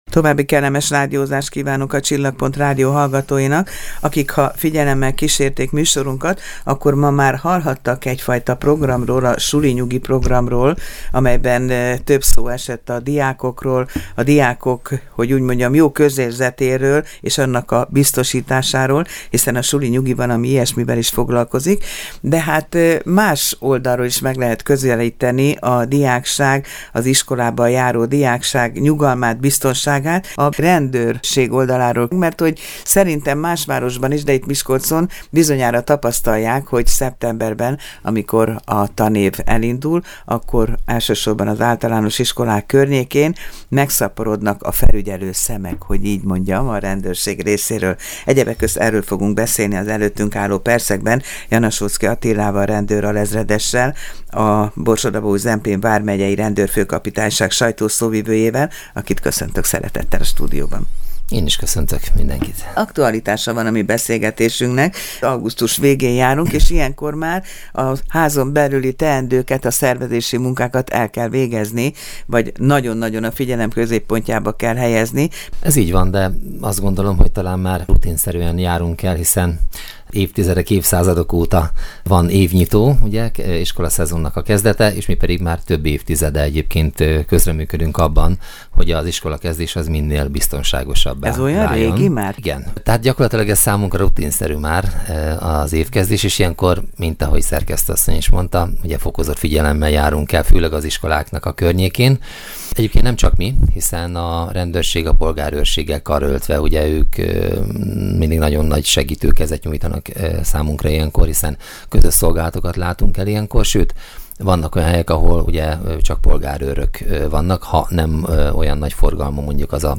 Mondta el a Csillagpont Rádió műsorában